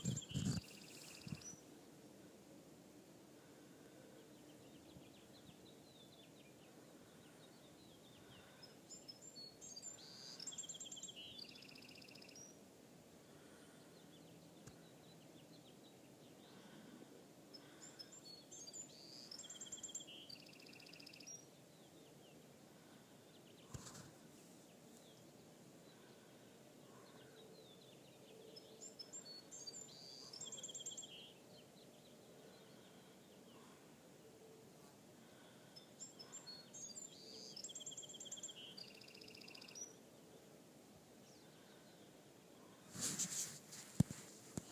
April morning, after the storm. Birds here.
april-morning-birds.m4a